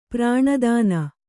♪ prāṇa dāna